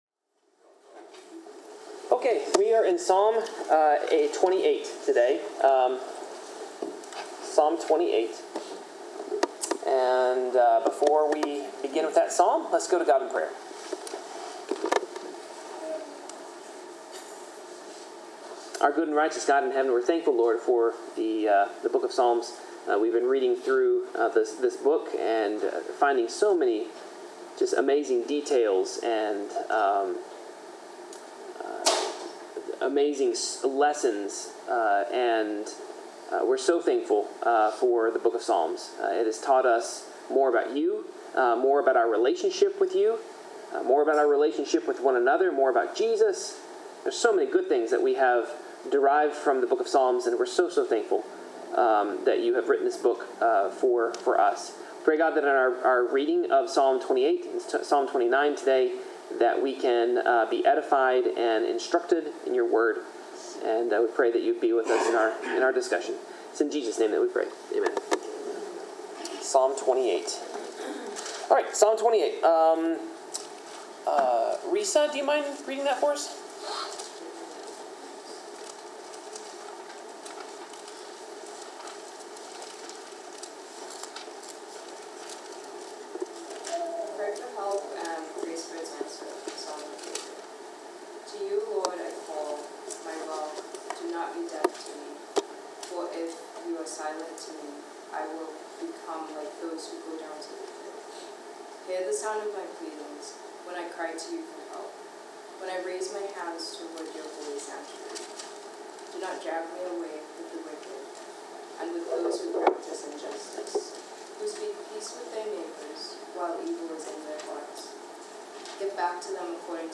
Bible class: Psalms 28-29
Passage: Psalms 28-29 Service Type: Bible Class